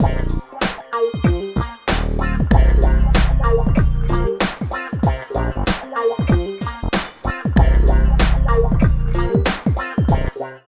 90 LOOP   -R.wav